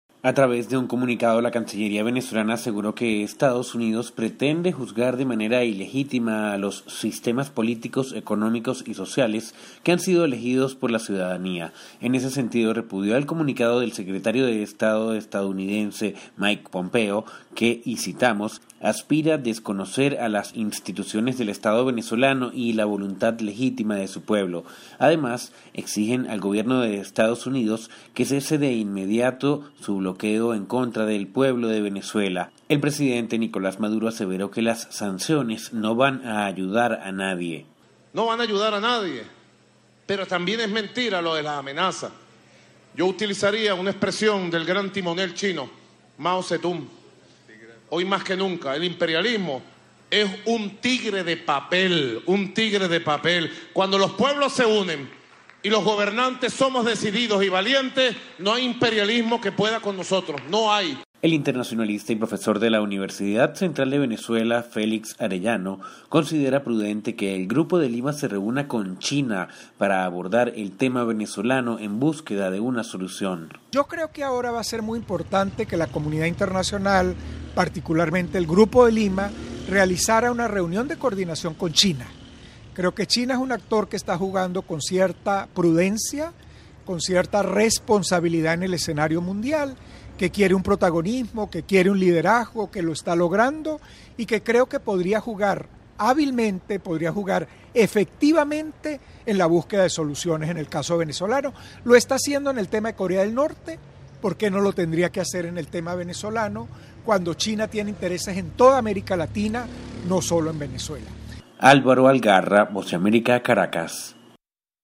El gobierno de Venezuela rechazó las declaraciones de Mike Pompeo, secretario de Estado de Estados Unidos, que a su juicio, pretendían desconocer a las instituciones gubernamentales de la nación sudamericana. Desde la Voz de América en Caracas, Venezuela informa